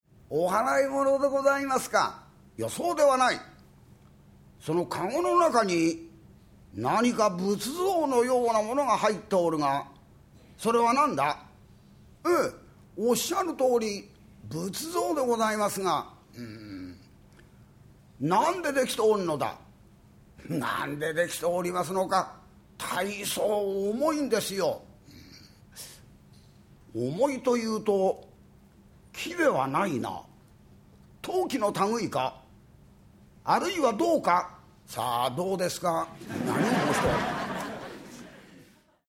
[オーディオブック] 桂歌丸「井戸の茶碗」
好評発売中！快調な語り口が心地よい“歌丸落語”の魅力を存分に味わえる名演集、
平成18年5月NHK「シブヤらいぶ館」の放送音源。